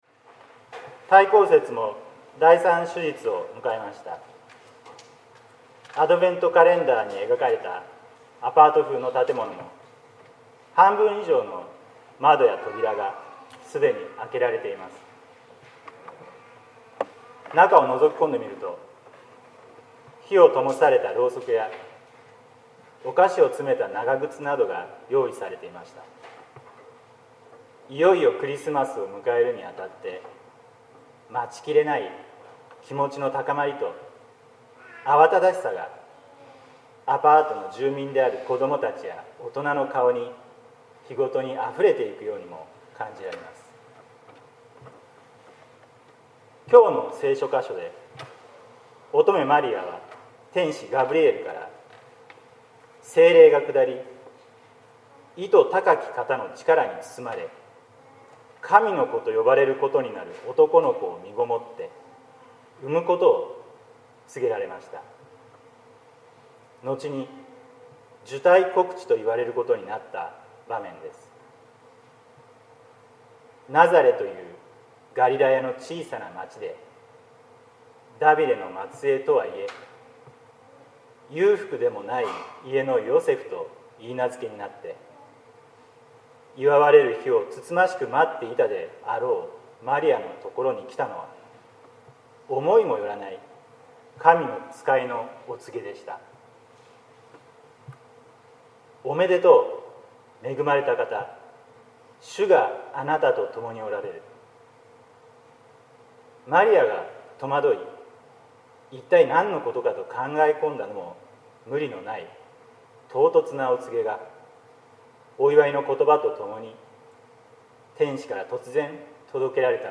説教「アヴェ・マリア 涙とともにいる人」（音声版）
待降節第３主日（2015年12月13日）